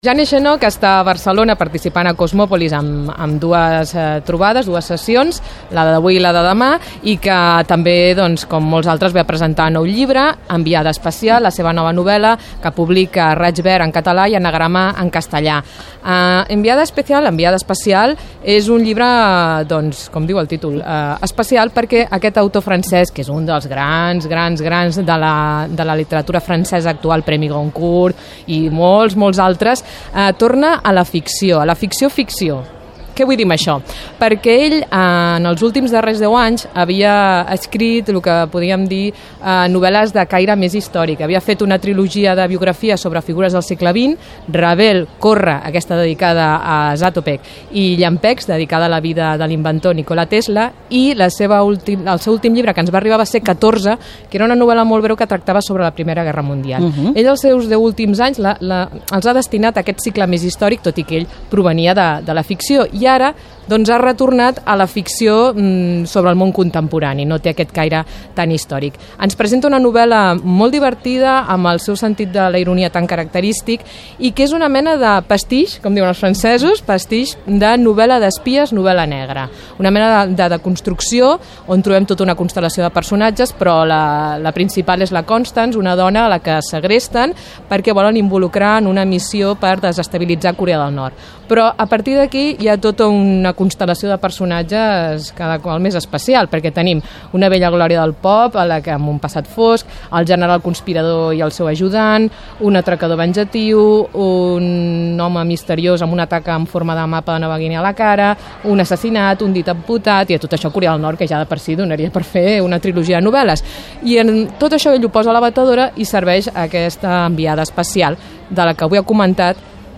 Roda de premsa de Jean Echenoz - Ràdio 4, 2017